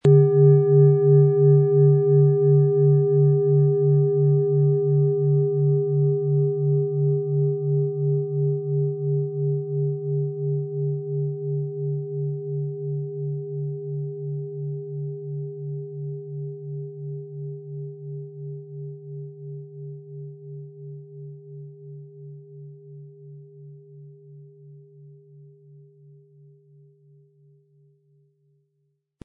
Planetenton
Die Planetenklangschale Mars wurde nach uralter Tradition in aufwendiger Handarbeit getrieben.
Sie möchten den schönen Klang dieser Schale hören?
Der kräftige Klang und die außergewöhnliche Klangschwingung der traditionellen Herstellung würden uns jedoch fehlen.
MaterialBronze